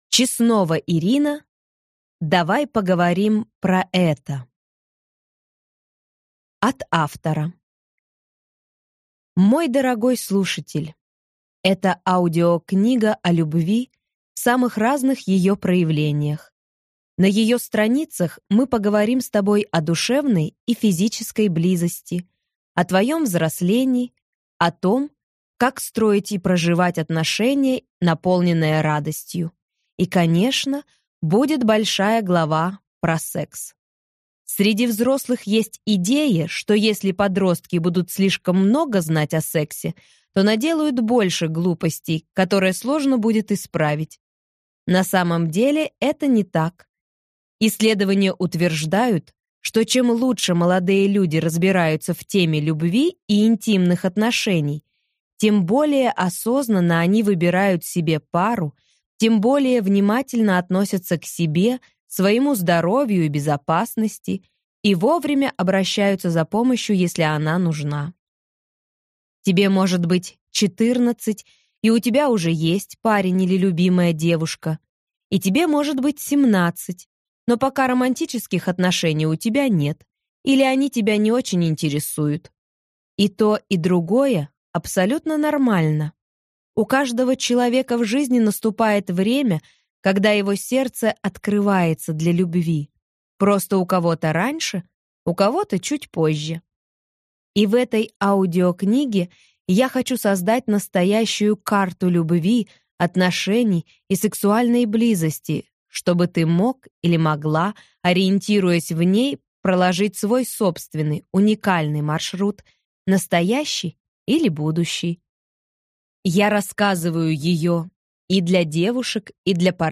Аудиокнига Давай поговорим про ЭТО | Библиотека аудиокниг